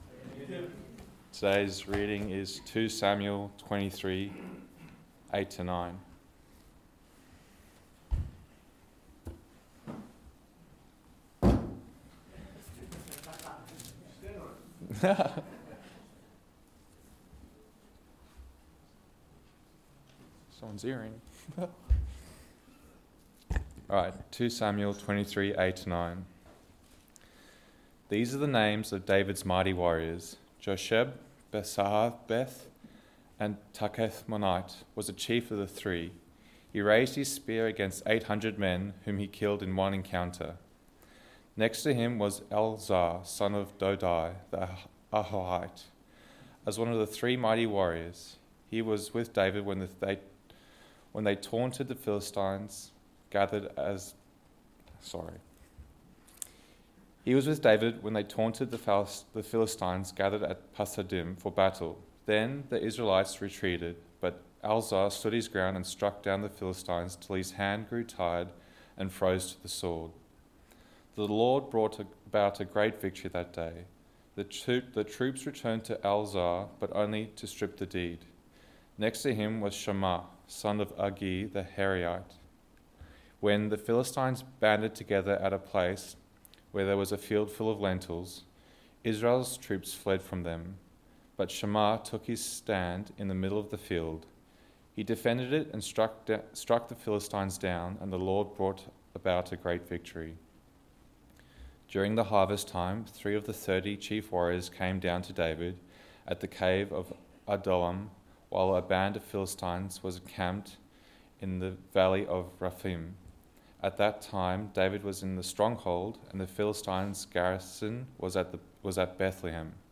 Text: 2 Samuel 23: 8-39 Sermon